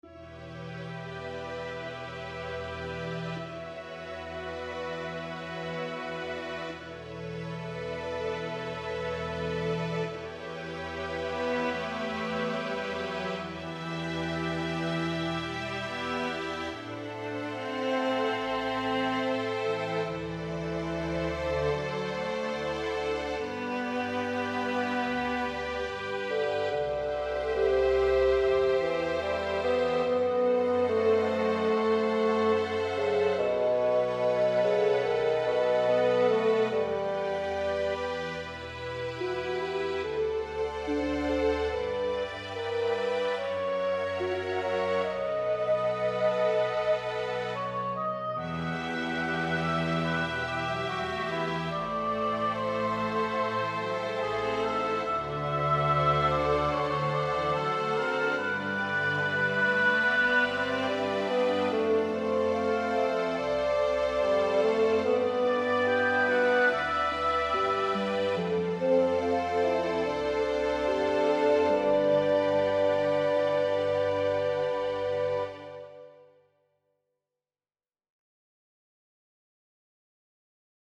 1ST VERSION: With French Horn instead of Solo Trumpet .
So, the only change in this version is to replace the Solo Trumpet with Solo French Horn.
La-nuit-Leila-v5-MP-French-Horn.mp3